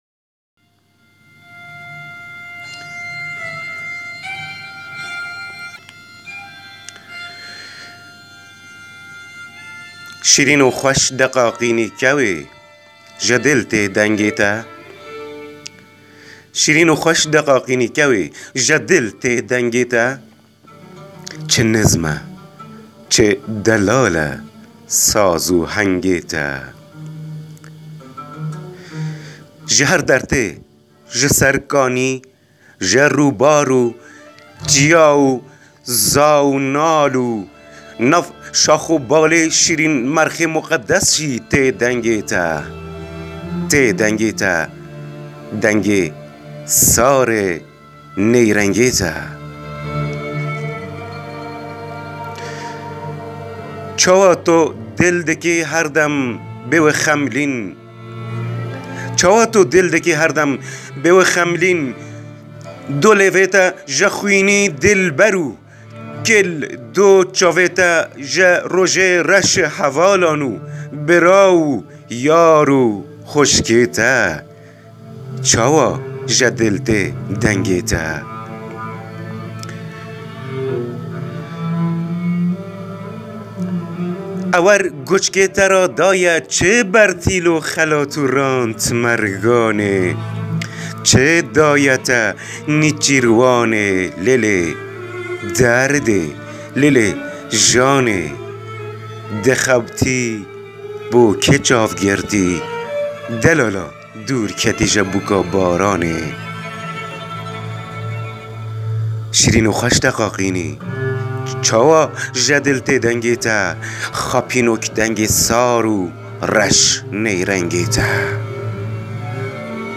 دکلمه